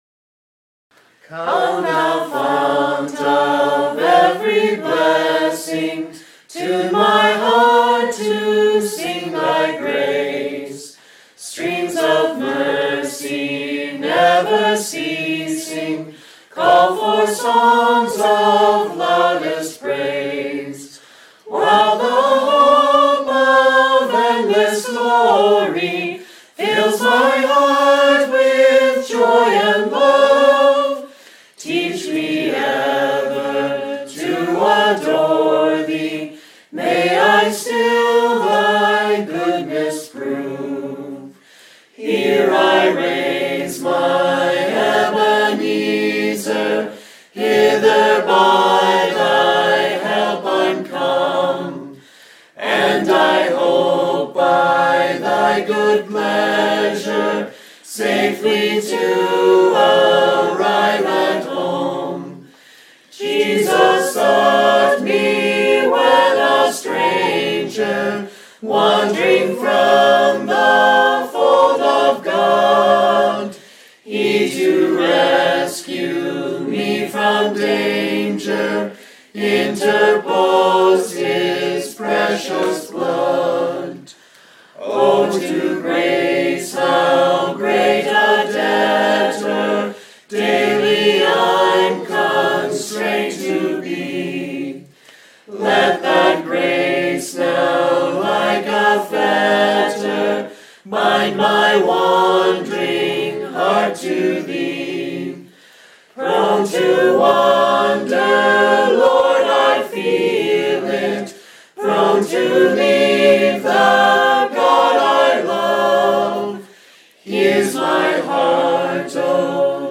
The files below use a recording made at our retreat in July, when a number of us learned the harmonies for this hymn.
The harmony part is always illustrated in the left channel by a piano.
Come Thou Fount — entire hymn with verse 1 in unison